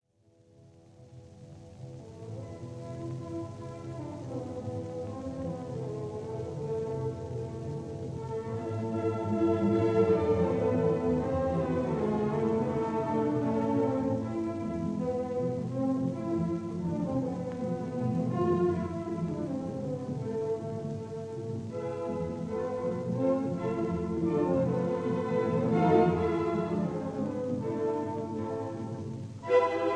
This is a 1946 recording
A minor, Andante: Allegro risoluto